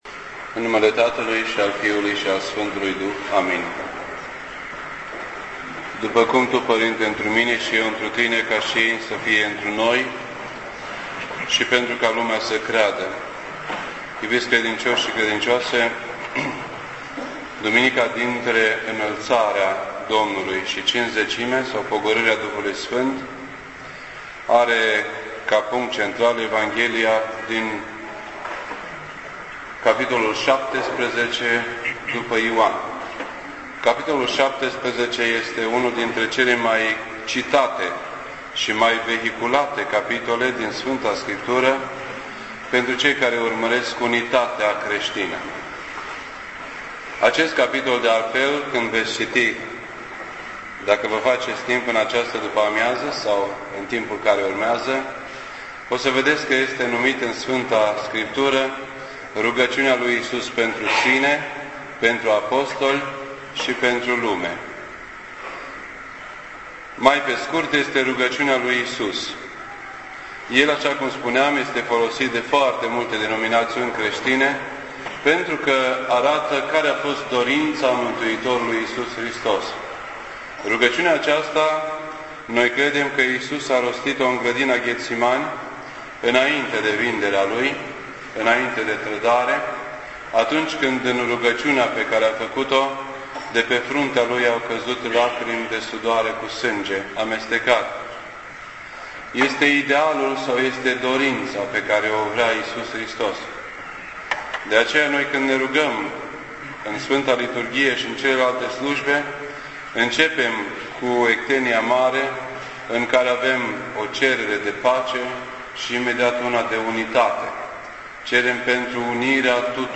This entry was posted on Sunday, May 31st, 2009 at 6:46 PM and is filed under Predici ortodoxe in format audio.